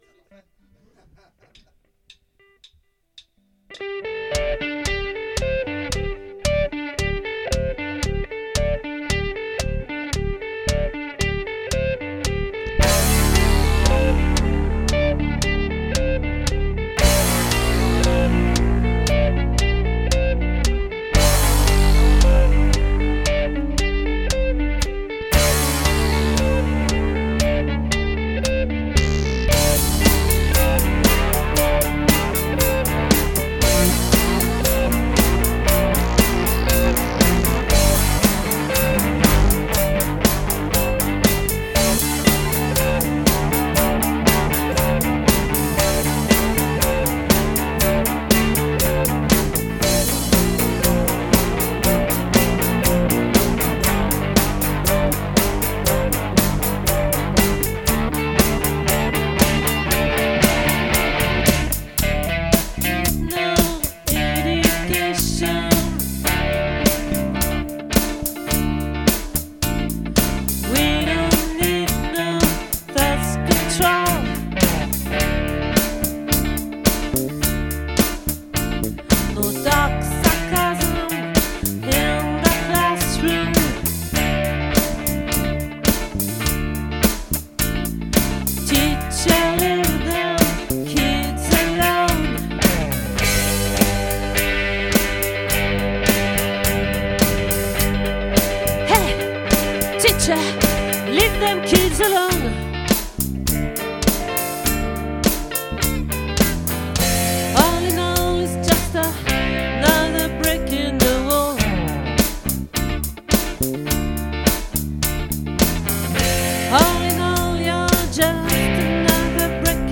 🏠 Accueil Repetitions Records_2024_03_13